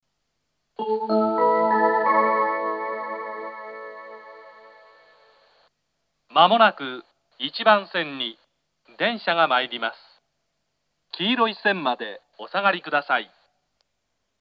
接近放送があり、「東海道型」の放送が使用されています。
１番線接近放送 男声の放送です。